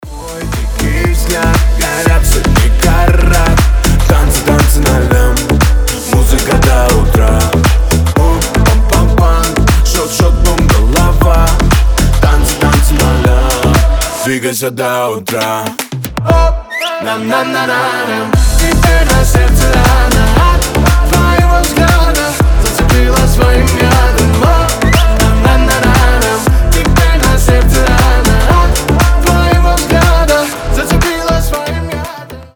• Качество: 320, Stereo
зажигательные